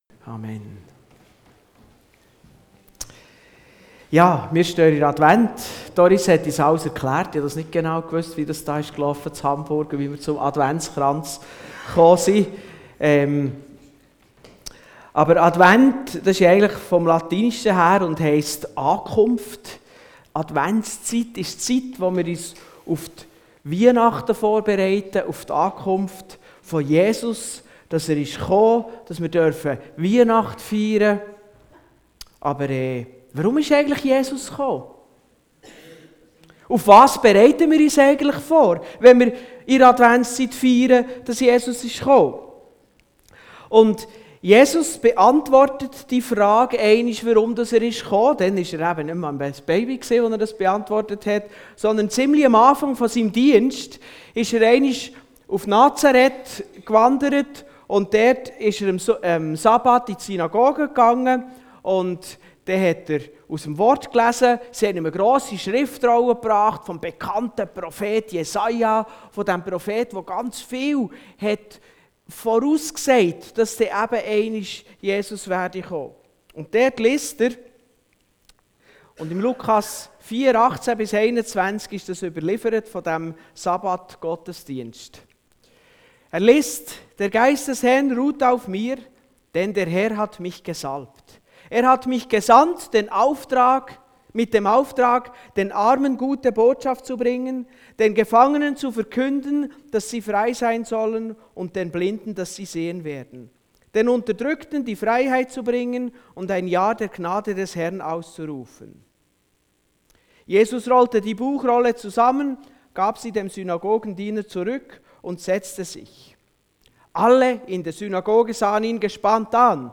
Allgemeine Themen Dienstart: Gottesdienst Dateien zum Herunterladen Notizen Themen